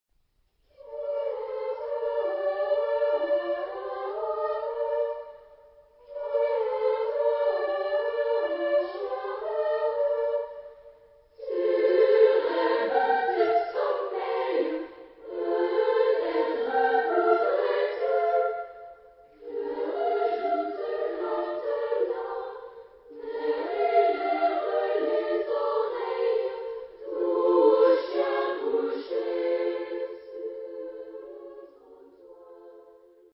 Genre-Stil-Form: weltlich ; Liedsatz ; neoklassisch
Charakter des Stückes: liebevoll ; besinnlich
Chorgattung: SSA  (3 Kinderchor ODER Frauenchor Stimmen )
Tonart(en): B-dur